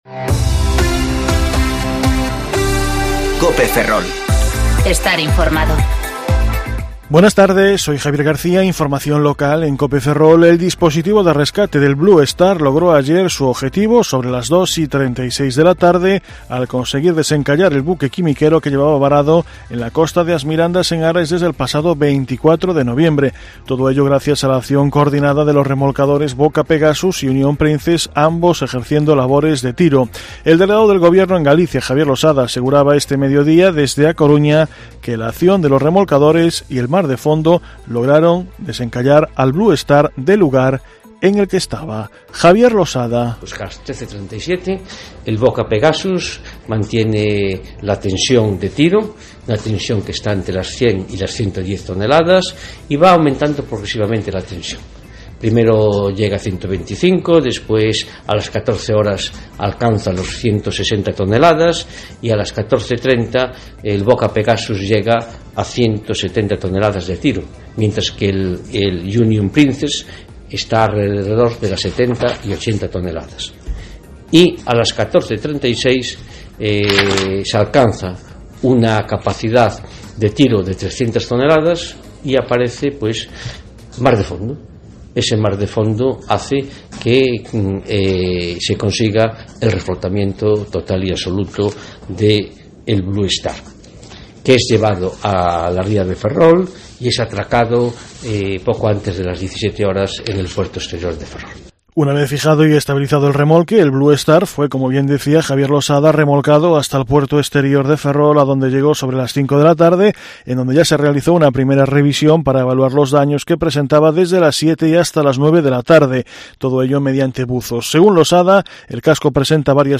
Informativo Mediodía Cope Ferrol 11/12/2019 (De 14.20 a 14.30 horas)